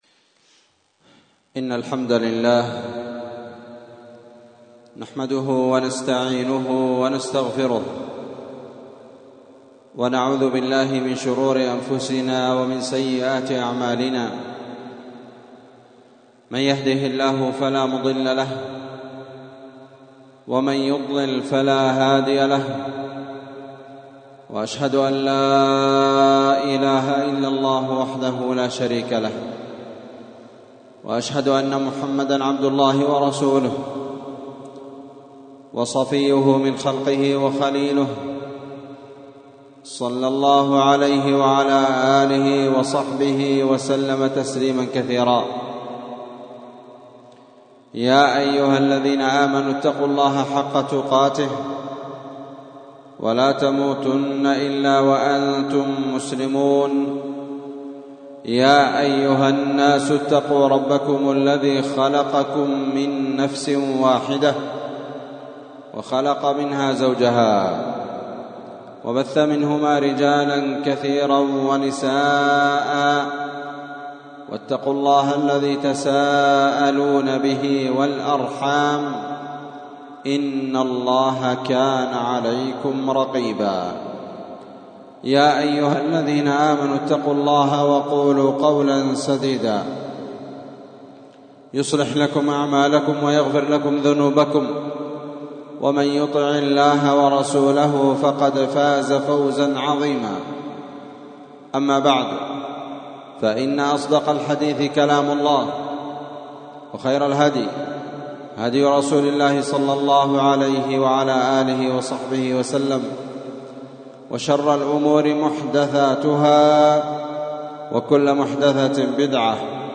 *خطبة الجمعة* ????????